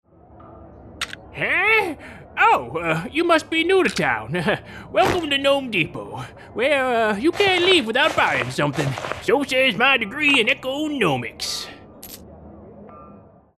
Professional French male voice artist offering performances in French & International English along with English & Swedish with a French accent. Young adult age range and ageless weird/funny voices.